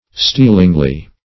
Search Result for " stealingly" : The Collaborative International Dictionary of English v.0.48: Stealingly \Steal"ing*ly\, adv. By stealing, or as by stealing, furtively, or by an invisible motion.